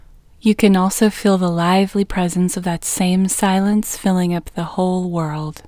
WHOLENESS English Female 11